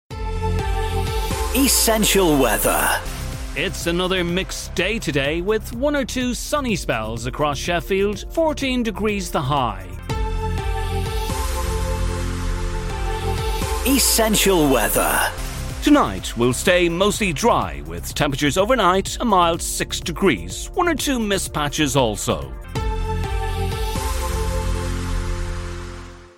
My accent is neutral english and my voice is fresh, warm, engaging and believable.
Soundproof studio and booth